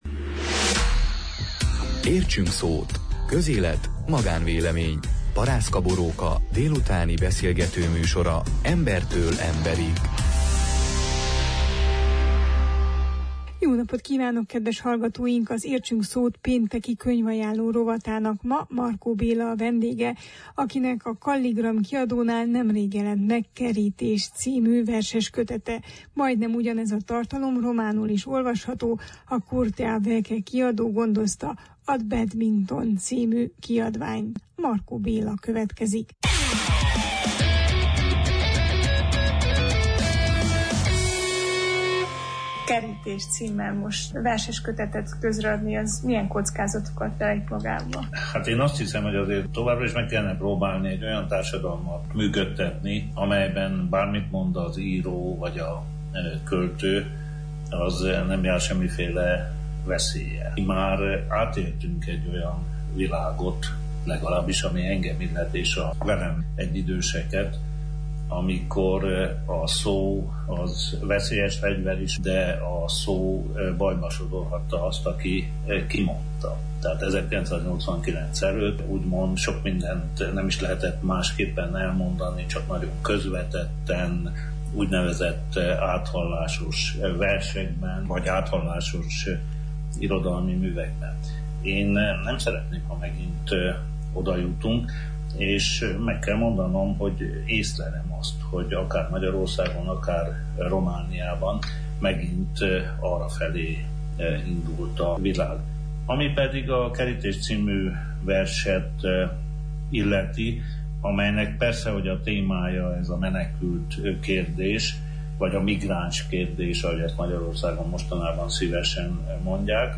Markó Bélával beszélgettünk